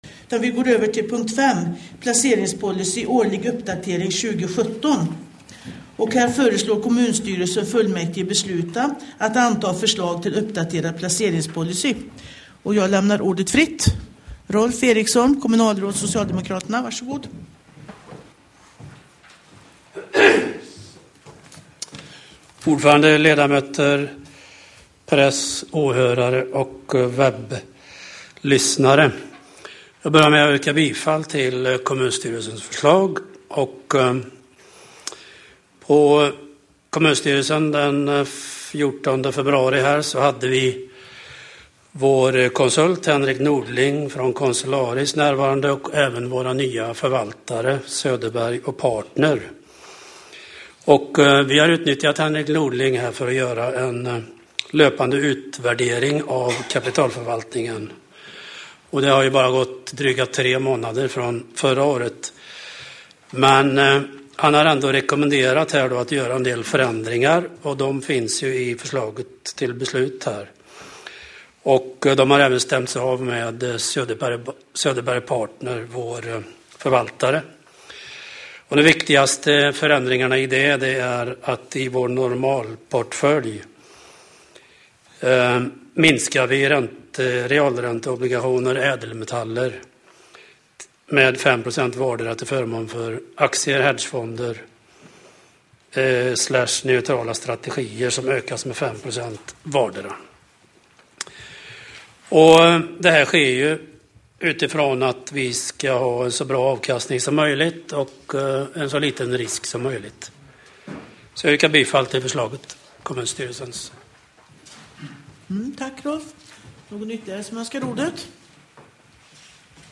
webbsändning från Tibor kommunfullmäktige
Kommunfullmäktige den 27 februari 2017 kl. 18.00.